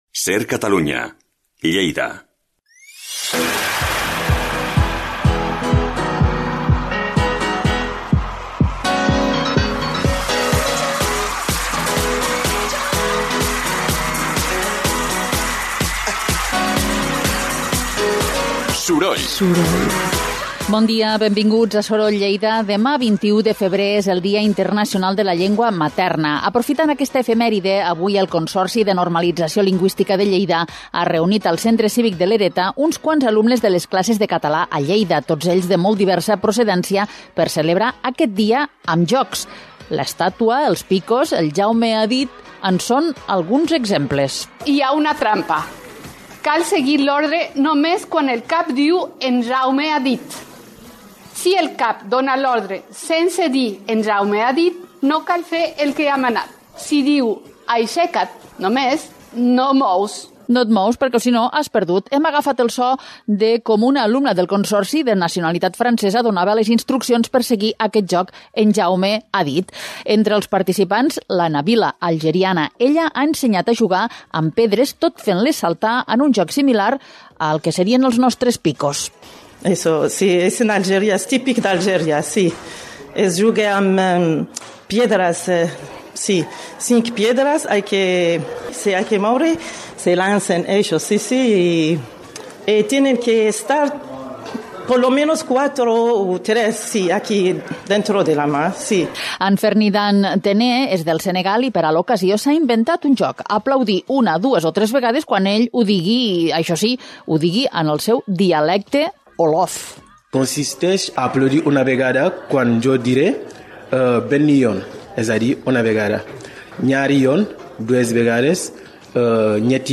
Indicatiu de l'emissora "SER Catalunya Lleida", indicatiu del programa, informació sobre el Dia Internacional de la Llengua Materna (el dia 21 de febrer), indicatiu i hora
Informatiu